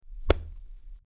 Body Hit 04
Body_hit_04.mp3